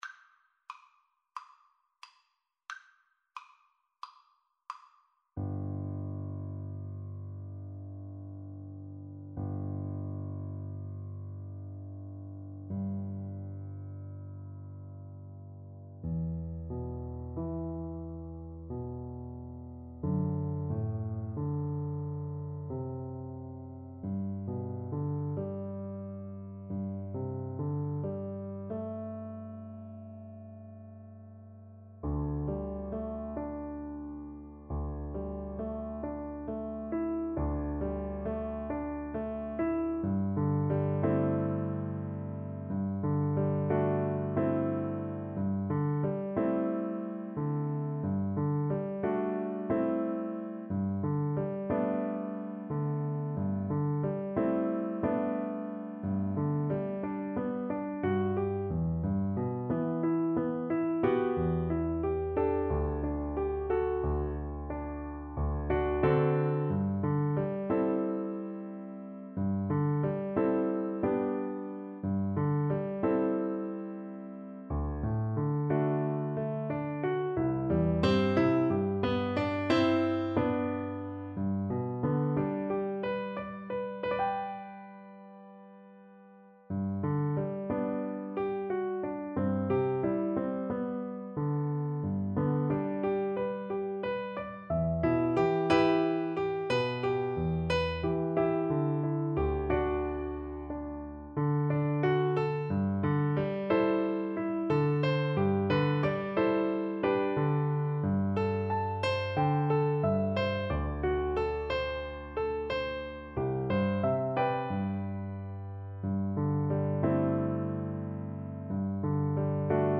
4/4 (View more 4/4 Music)
Andante cantabile = c. 90